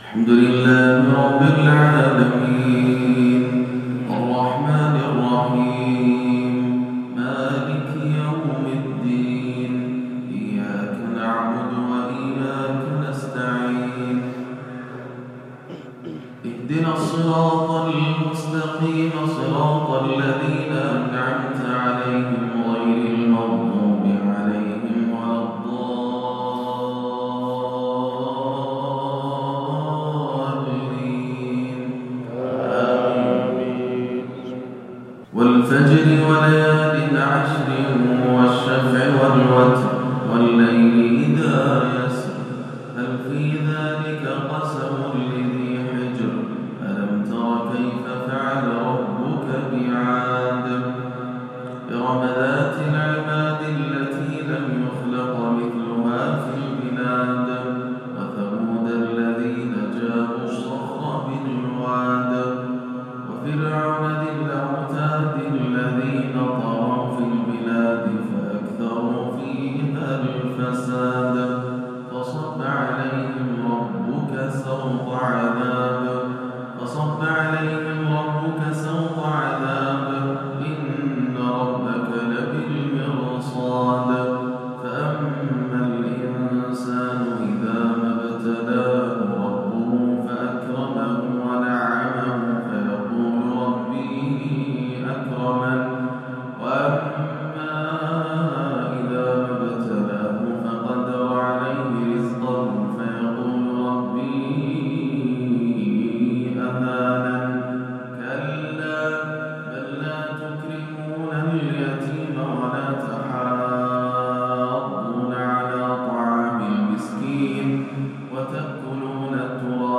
كلا إذا دكت الأرض دكاً دكاً - انتقالة مؤثرة من الرست إلى الصبا - سورتي الفجر والهمزة - فجر يوم الثلاثاء 5/20 > عام 1439 > الفروض - تلاوات ياسر الدوسري